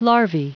Prononciation du mot larvae en anglais (fichier audio)
Prononciation du mot : larvae